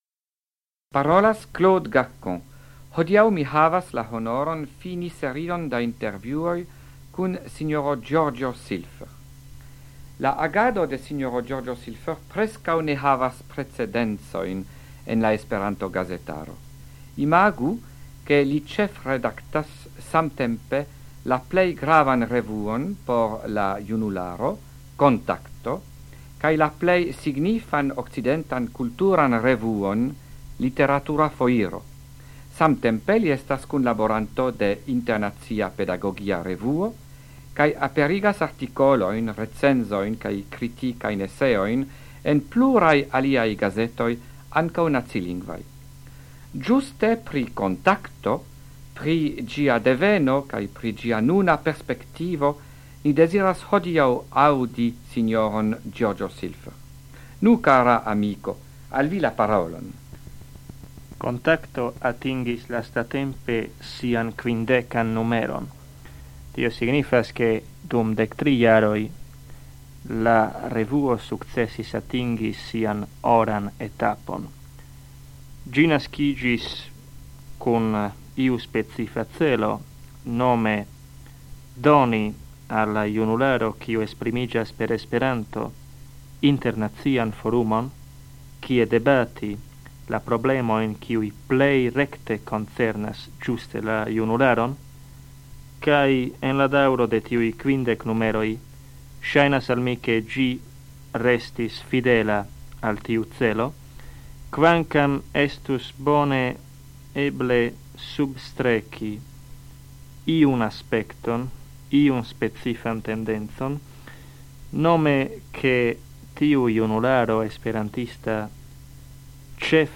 Radioprelegoj en la jaro 1977
Intervjuo